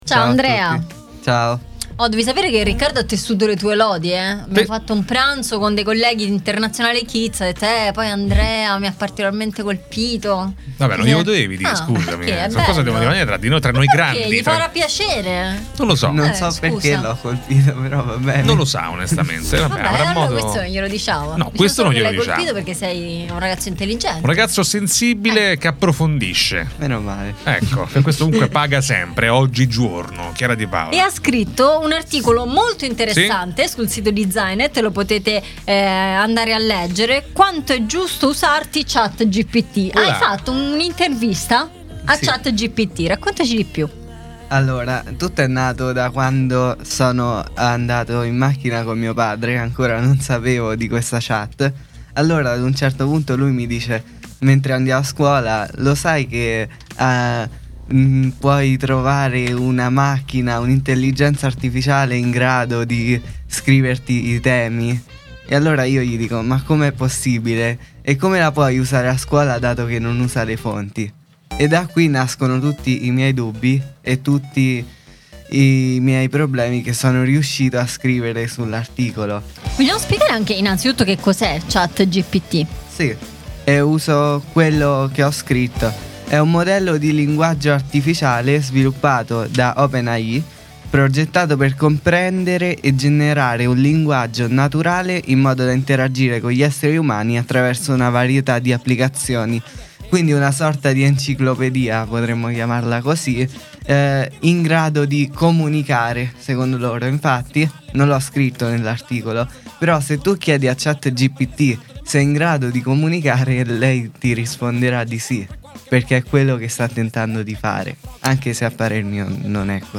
Intervista a ChatGPTID